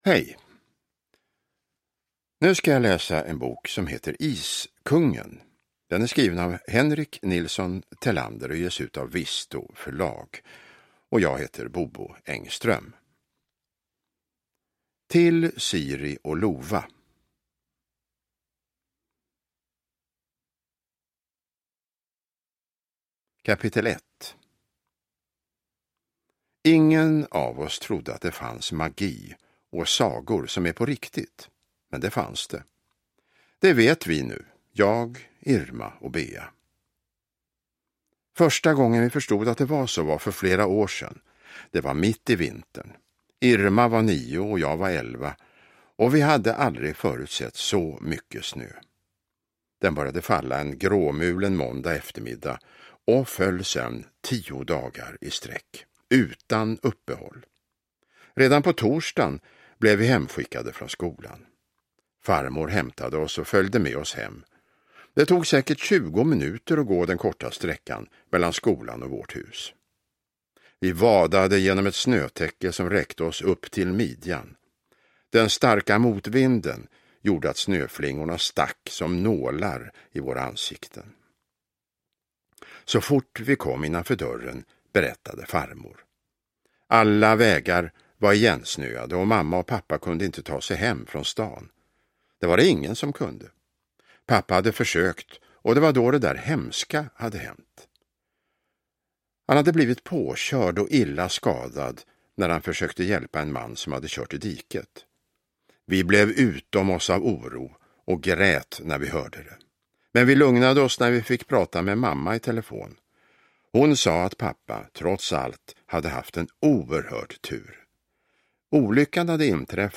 Iskungen (ljudbok) av Henrik Nilsson Thelander